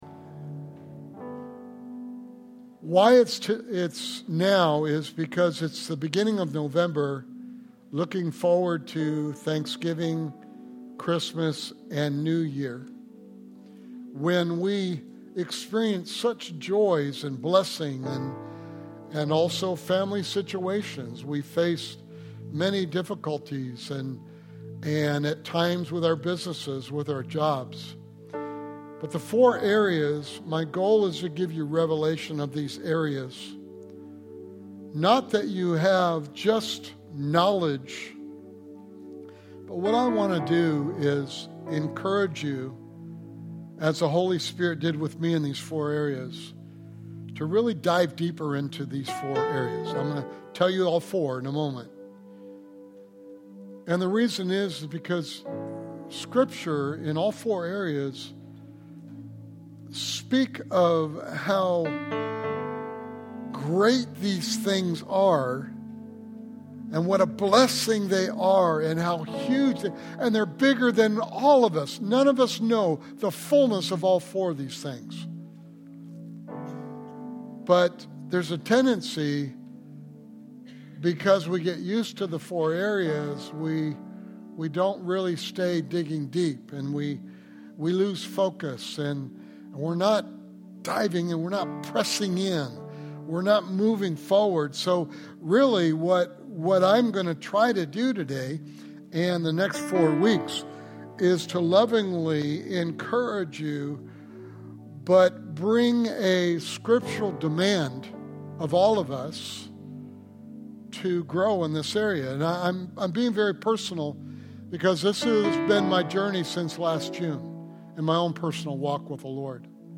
Sermon Series: Becoming the Better You